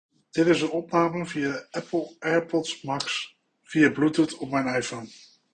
Microfoon
Het is geen hoogwaardige studiomicrofoon, maar hij voldoet prima voor het (video)bellen of aanroepen van Siri. De microfoon pakt bijna geen achtergrondgeluid op. Hieronder kan je luisteren hoe het geluid klinkt, opgenomen met de AirPods Max op mijn iPhone 13 Pro.
Apple-AirPods-Max-microfoon.mp3